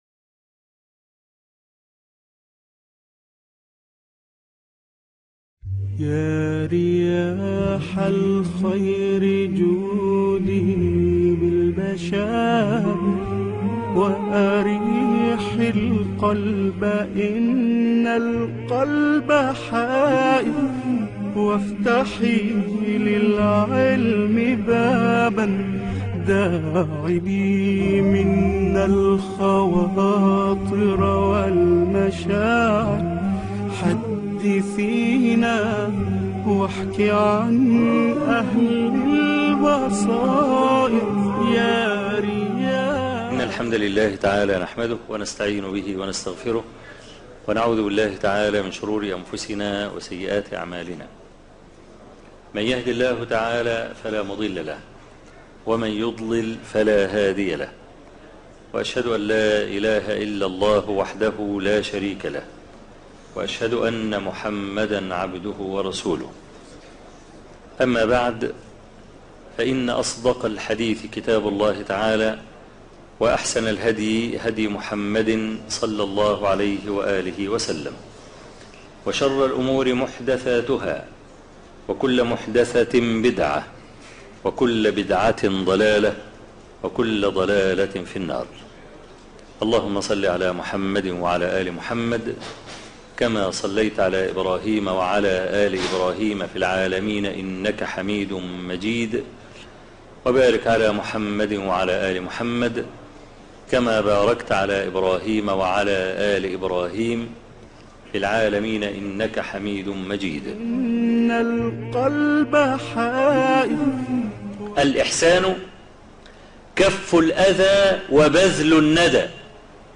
الدرس ( 8) بصائر - الشيخ أبو إسحاق الحويني